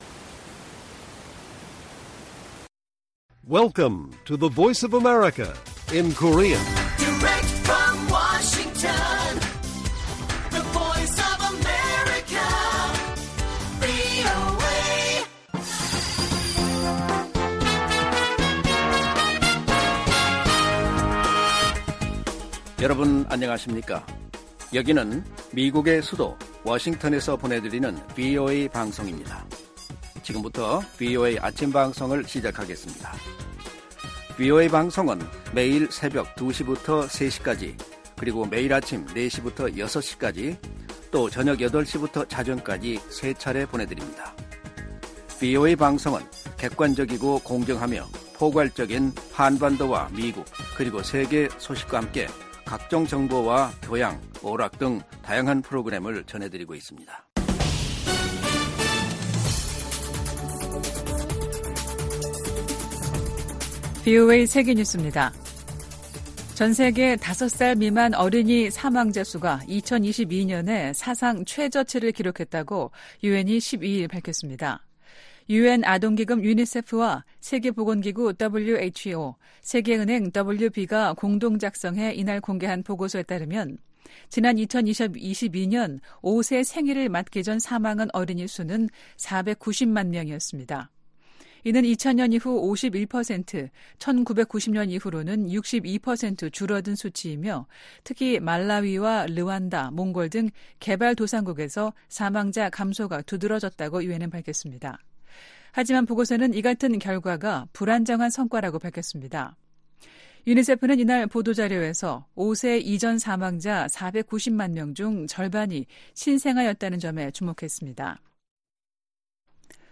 세계 뉴스와 함께 미국의 모든 것을 소개하는 '생방송 여기는 워싱턴입니다', 2024년 3월 14일 아침 방송입니다. '지구촌 오늘'에서는 필요하면 핵무기를 쓸 준비가 돼 있다고 블라디미르 푸틴 러시아 대통령이 밝힌 소식 전해드리고, '아메리카 나우'에서는 조 바이든 대통령과 도널드 트럼프 전 대통령이 각각 민주·공화당 경선에서 대통령 후보 지명에 필요한 대의원 수 과반을 확보한 이야기 살펴보겠습니다.